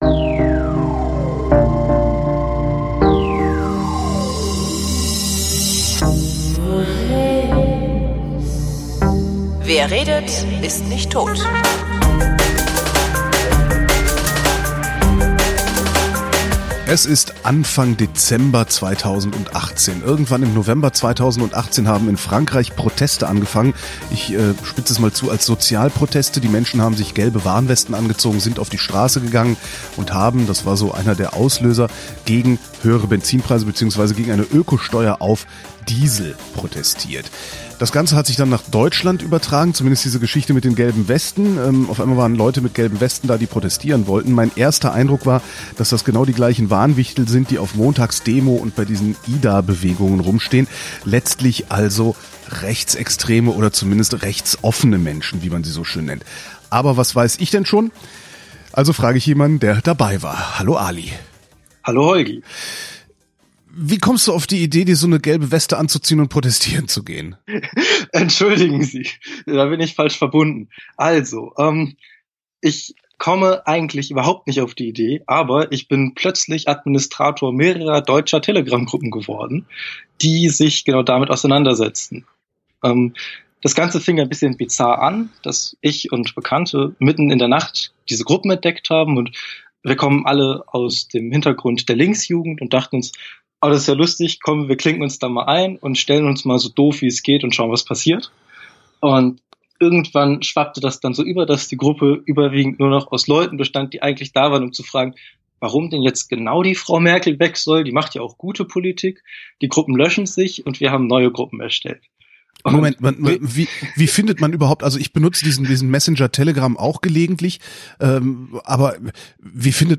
Bitte entschuldigt die schlechte Leitungsqualität.